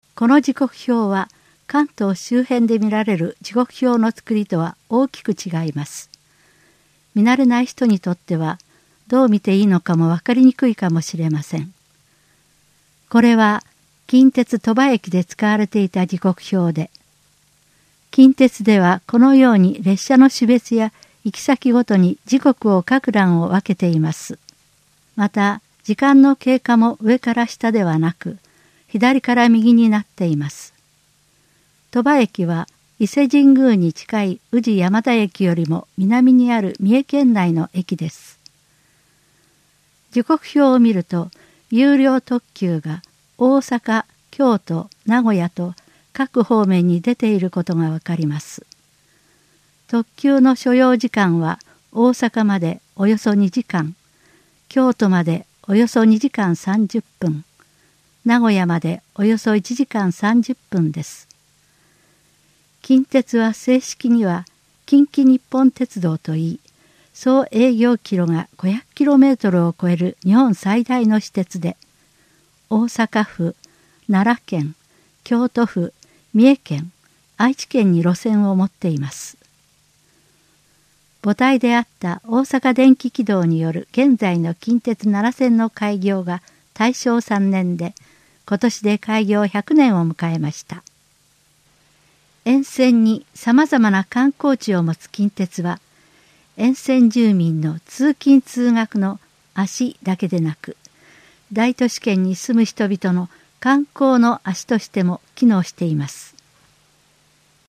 スマートフォンを利用した音声ガイド
※音声データの作成は、音訳ボランティアグループ「福生いとでんわ」にご協力いただきました。